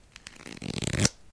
cardFan2.ogg